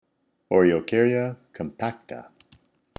Pronunciation/Pronunciación:
O-re-o-car-ya com-pác-ta